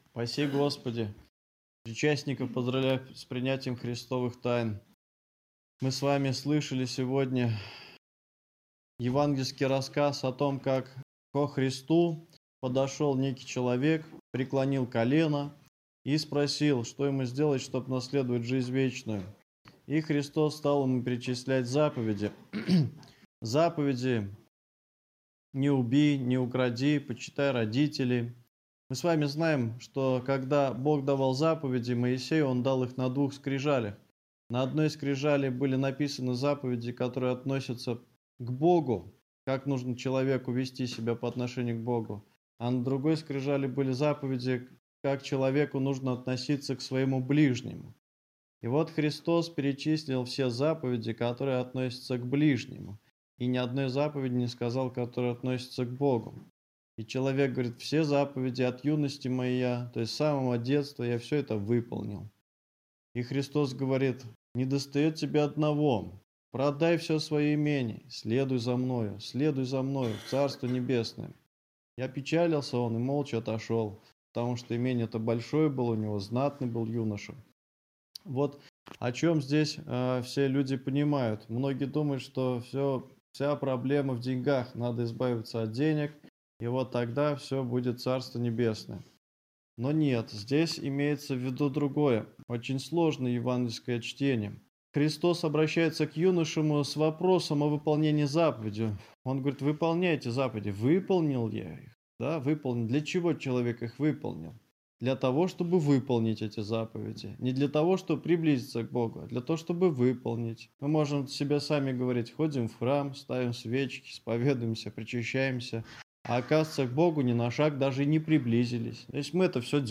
Аудио: проповедь о богатом юноше, не захотевшем войти в Царство Небесное
Проповедь-на-утреннией-службе-16-декабря.mp3